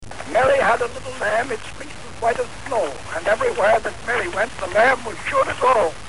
December 6  Edison records 'Mary had a little lamb' onto a cylinder wrapped with tin foil on his newly completed prototype hand-cranked phonograph at Menlo Park, NJ—the earliest recording of a human voice.